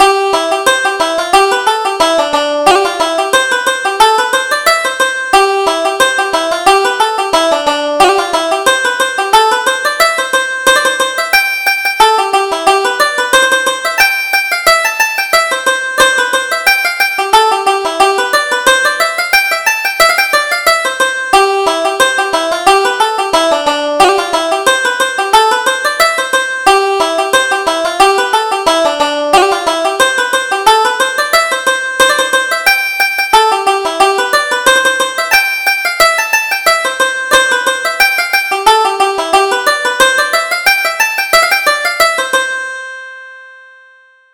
Reel: William White's Reel